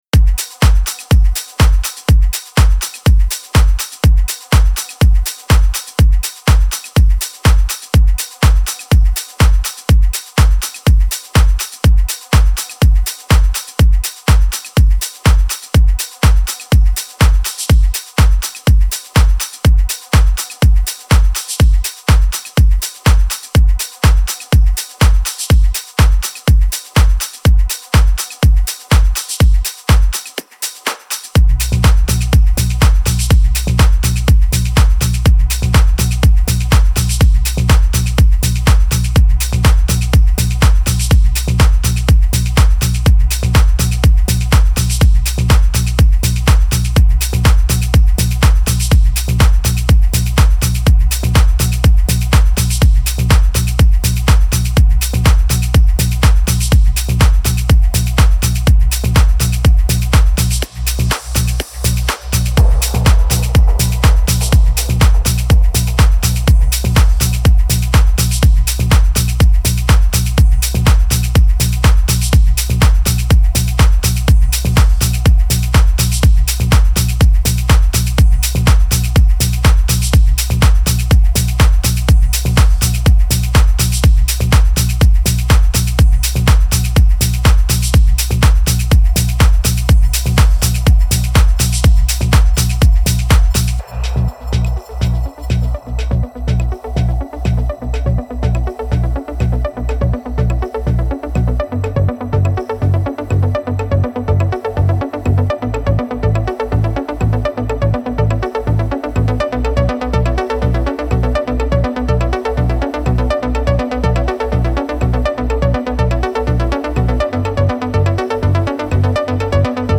پر‌انرژی
موسیقی بی کلام تکنو